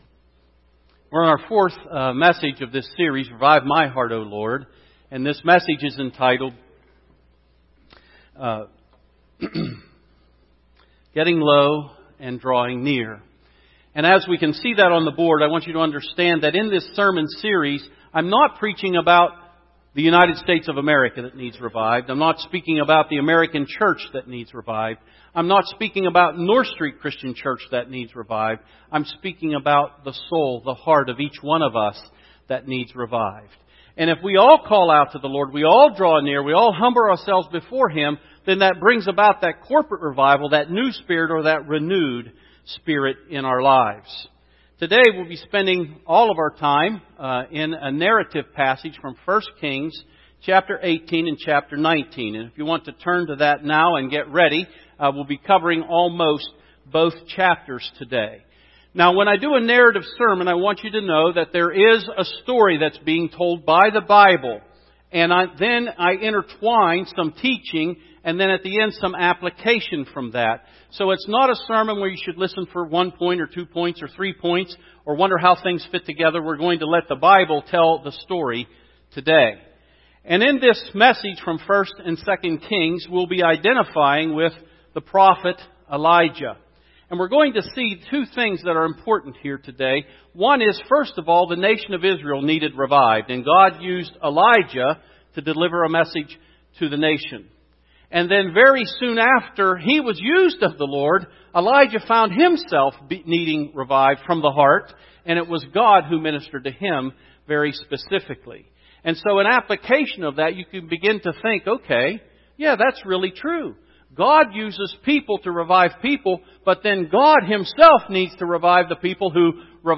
Sermons – North Street Christian Church